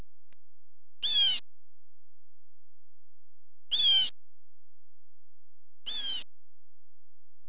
Listen for a "queedle, queedle" musical kind of song, with the "quee" being higher than the "dle" part. It can also belt out a raucous "jay-jay" call.
Blue Jay MP3 file
bluejay.wav